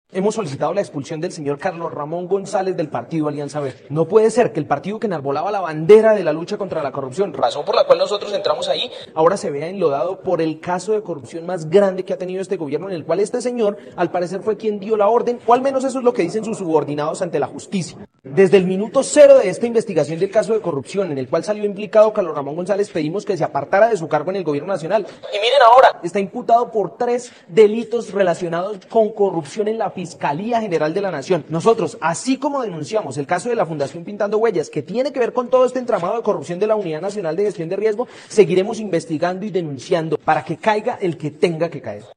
Cristian Avendaño, Representante a la Cámara